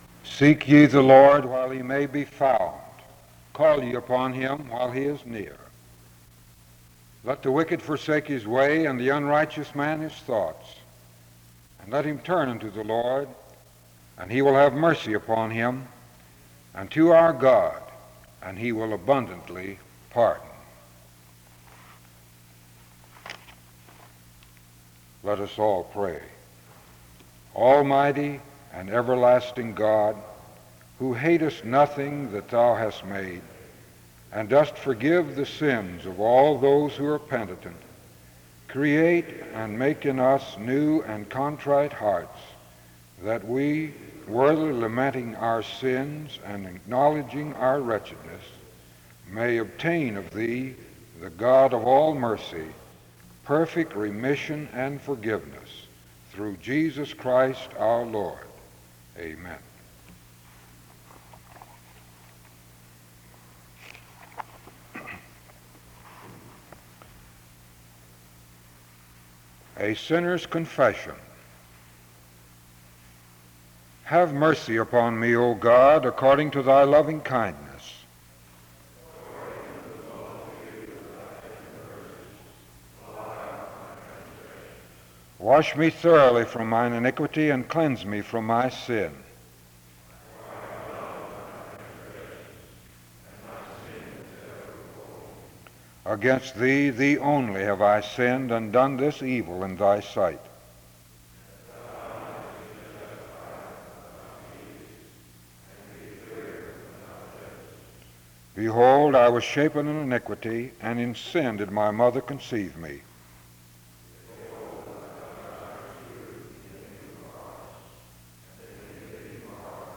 The service starts with an opening prayer from 0:00-1:00. “A Sinner’s Confession” is read from 1:09-2:55. Music plays from 3:06-8:26.
A prayer is offered from 8:40-9:47.
Location Wake Forest (N.C.)